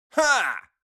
MaleGrunt3.wav